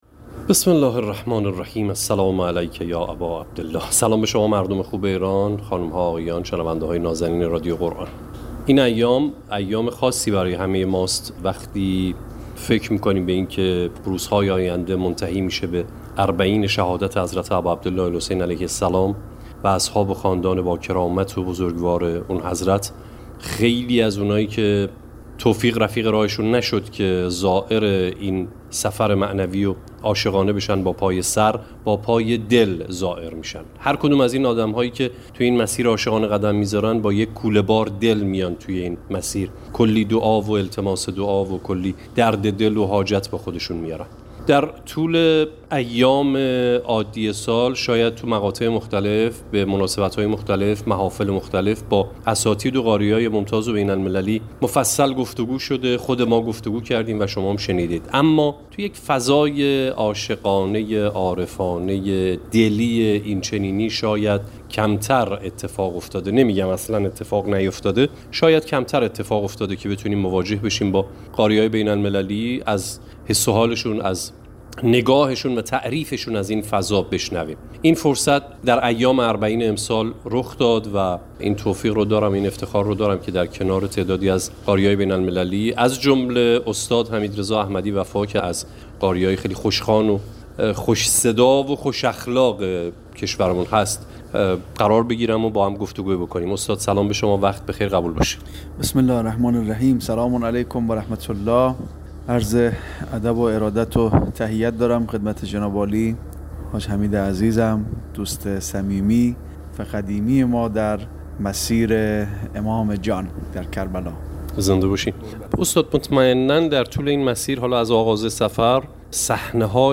قاری بین‌المللی کشورمان که در مواکب مسیر پیاده‌روی اربعین حسینی در مسیر نجف به کربلا به تلاوت قرآن می‌پردازد، حال و هوای تلاوت در چنین اوقاتی را ویژه توصیف کرد.